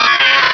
Cri de Ténéfix dans Pokémon Rubis et Saphir.